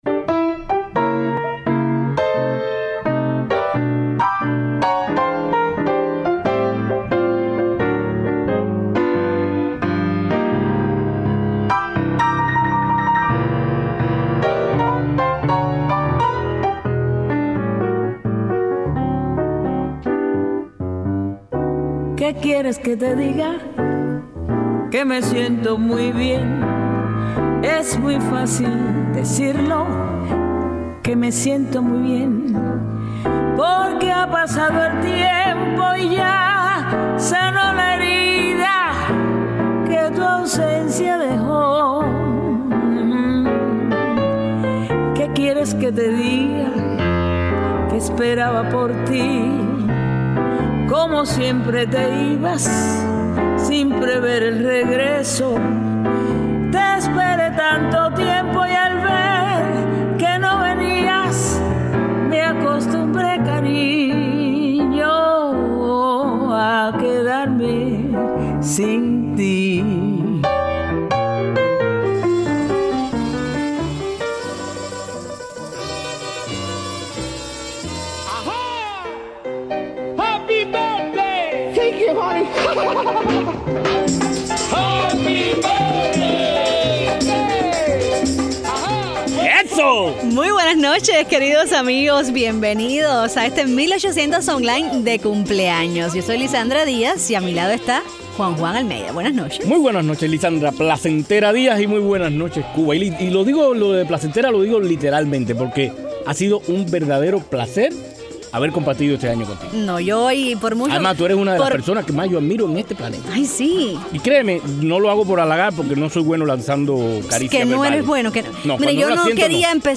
El 9 de enero estuvimos celebrando el primer año de 1800 Online. Elogios, críticas, recomendaciones;